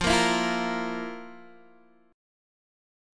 FM7sus4 chord